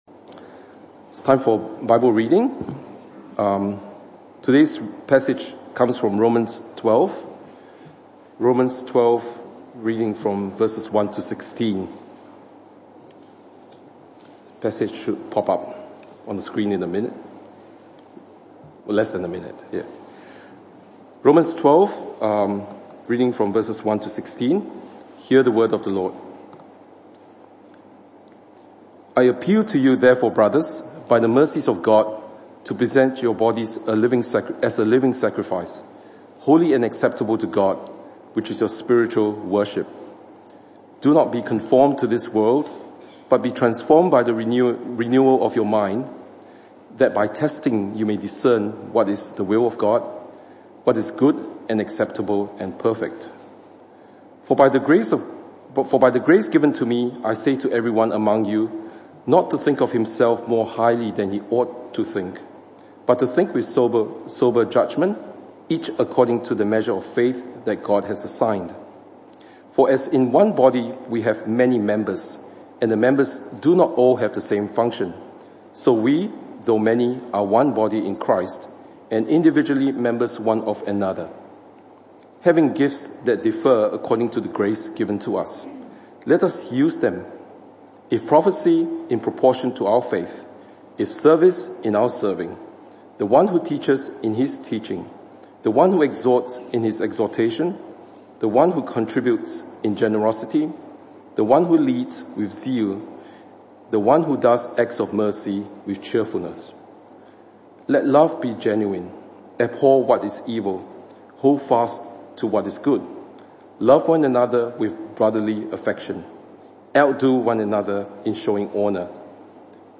Romans 12:1-21 Service Type: Morning Service This talk was part of the AM/PM Service series entitled Loving Your Church.